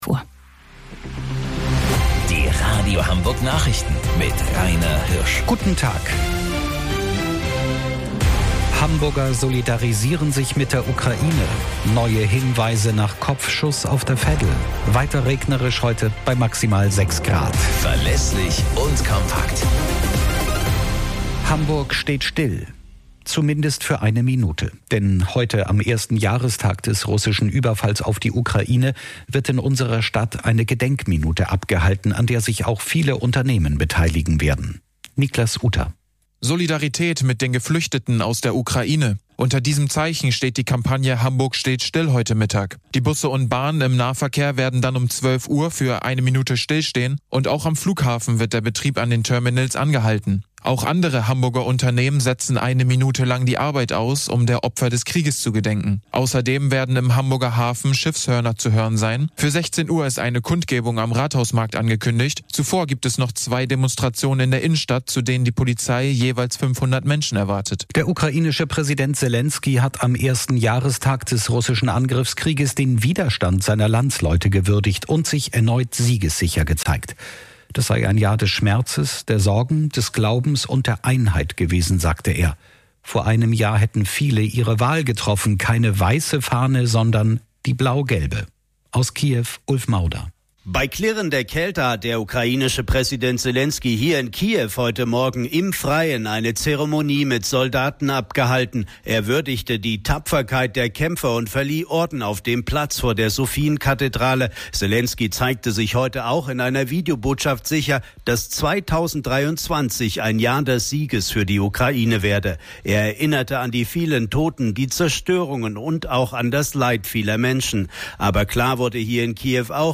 Radio Hamburg Nachrichten vom 03.10.2022 um 12 Uhr - 03.10.2022